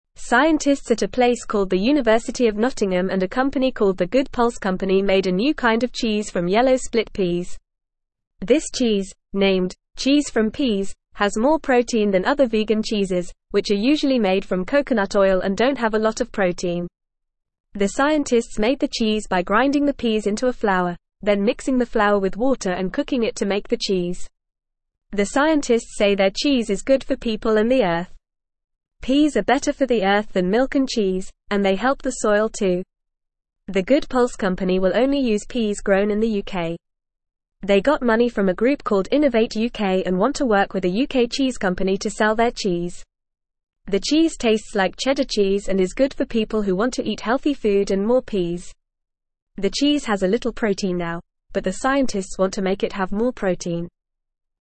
Fast
English-Newsroom-Beginner-FAST-Reading-New-Cheese-Made-from-Peas-Tasty-and-Healthy.mp3